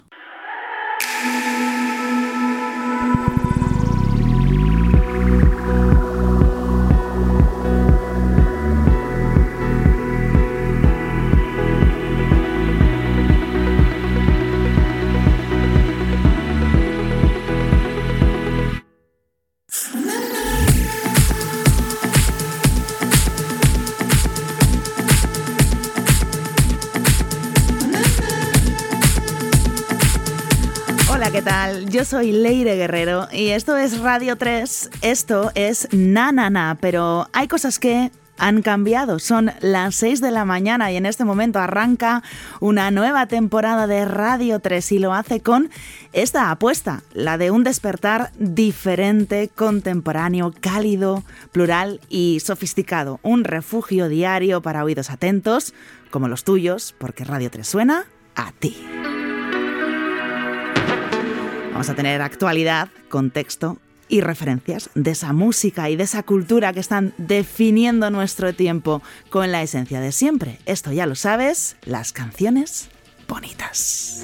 Musical
Fragment extret de RNE audio.